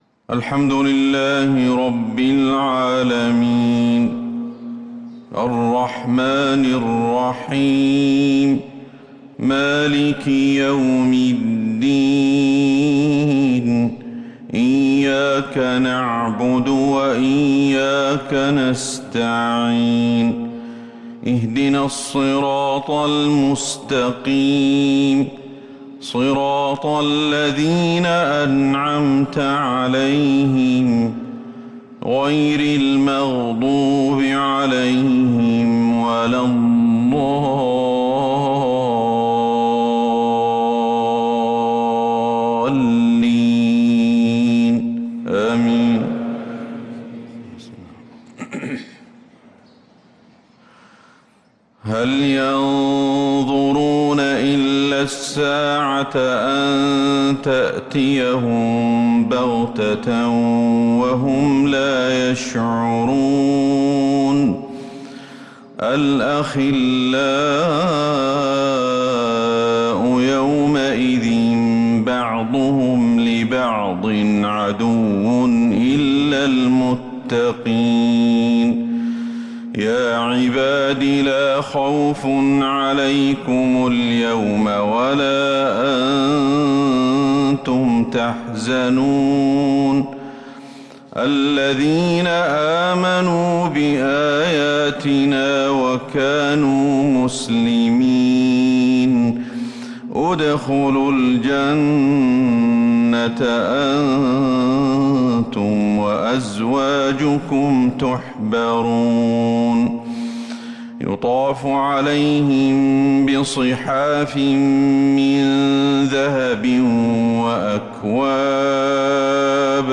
Fajr prayer from Surat Az-Zukhruf 4-1-2025 > 1446هـ > الفروض